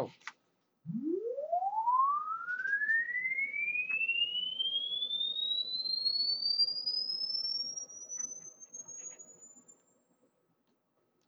I checked on the EVM with a DMIC similar to this MP34DT01-M using the preset configuration like the above and I can hear the recording.
Here is the DCLK and Data as well as the recording export from Audacity without any white noise just the tone.
DMIC-recording.wav